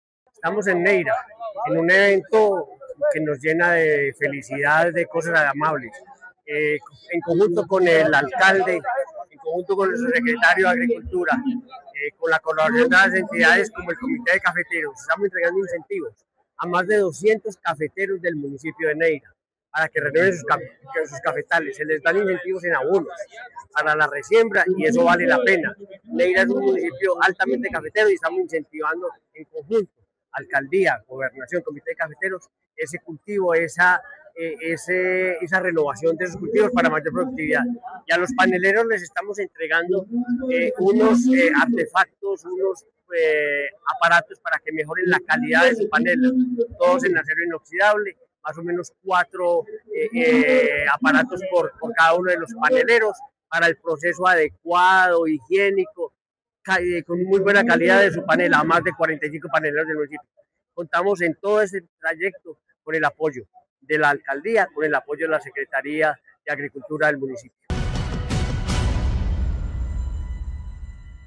Henry Gutiérrez Ángel, gobernador de Caldas